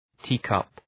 Προφορά
{‘ti:kʌp}